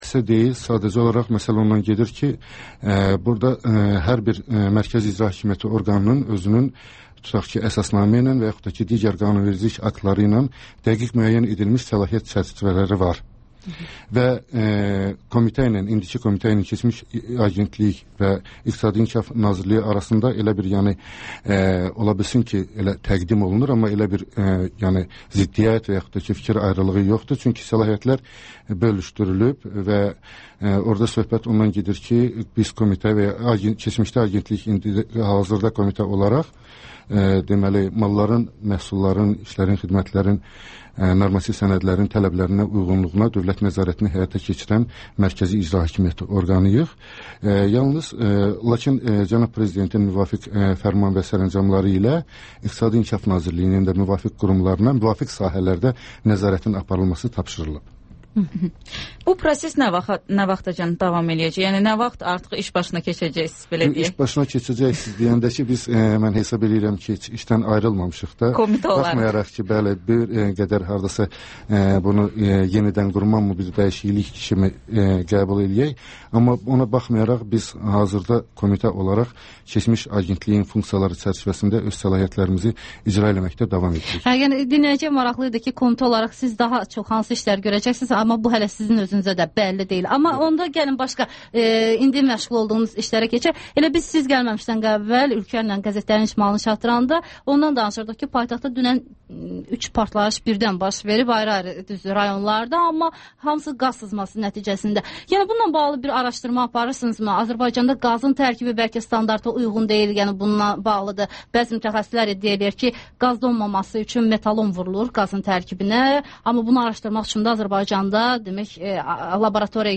Ölkənin tanınmış simaları ilə söhbət (Təkrar)